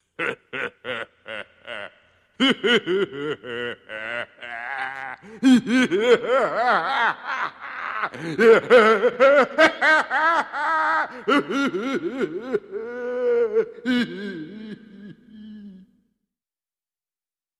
Laughter
Maniacal laughter, one man